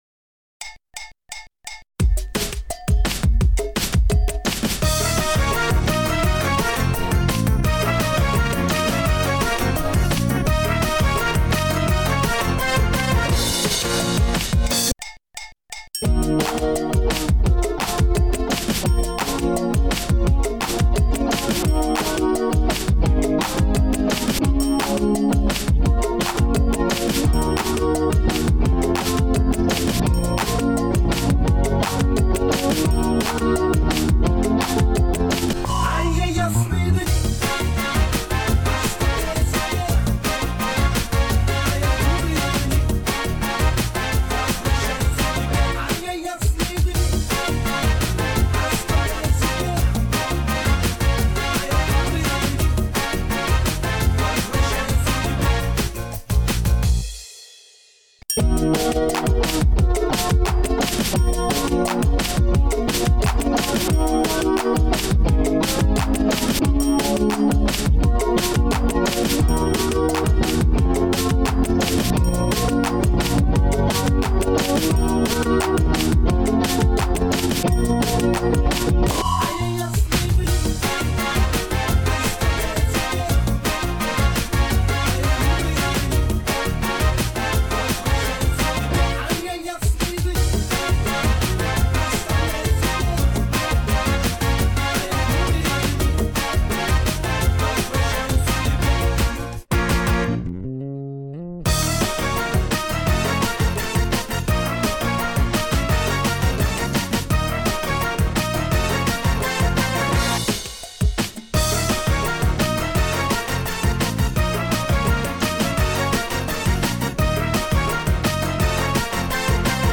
Пойте караоке
минусовка версия 226486